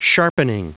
Prononciation du mot sharpening en anglais (fichier audio)
Prononciation du mot : sharpening